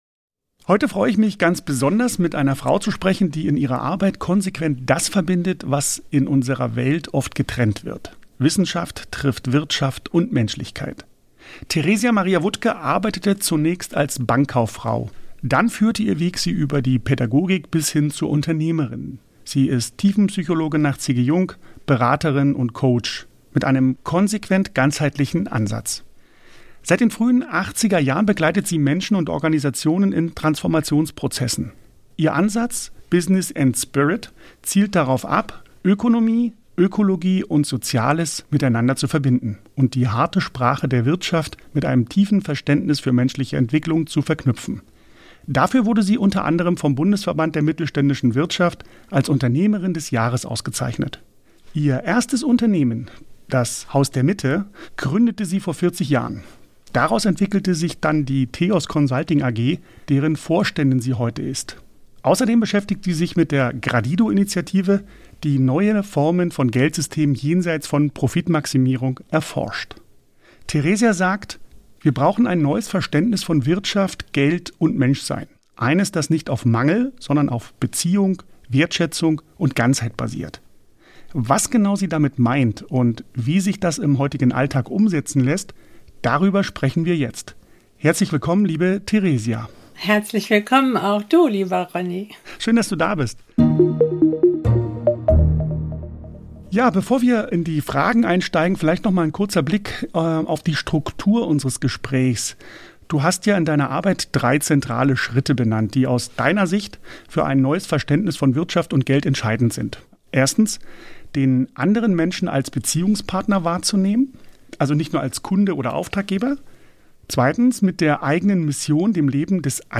Ein Gespräch über unternehmerischen Mut, systemisches Denken und die Kraft menschlicher Beziehungen als Wirtschaftsfaktor.